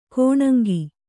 ♪ kōṇaŋgi